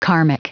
Prononciation du mot karmic en anglais (fichier audio)
Prononciation du mot : karmic